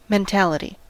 Ääntäminen
Synonyymit mindset Ääntäminen US Tuntematon aksentti: IPA : /menˈtæləti/ Haettu sana löytyi näillä lähdekielillä: englanti Määritelmät Substantiivit A mindset ; a way of thinking .